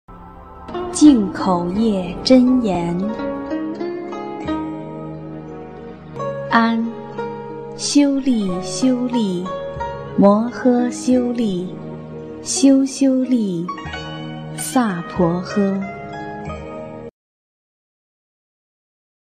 音频：经文教念-《净口业真言》